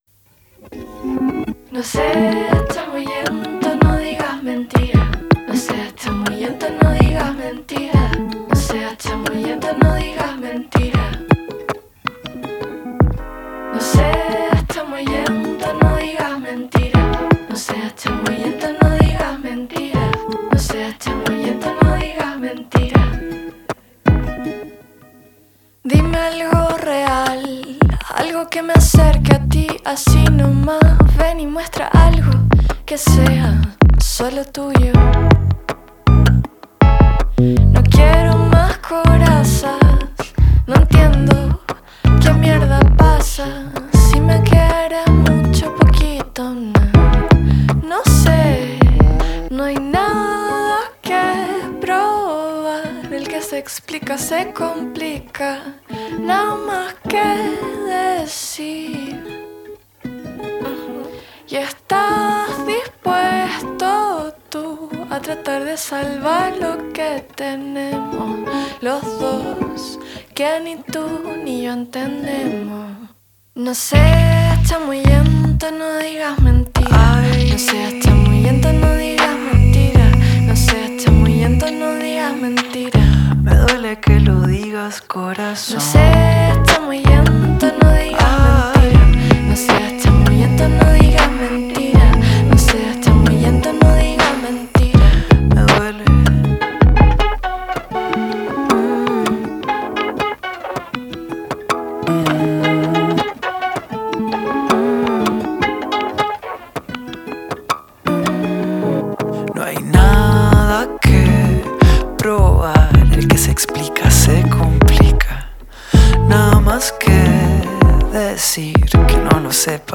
album mélancolique tout en acoustique...
agrémenté d'electro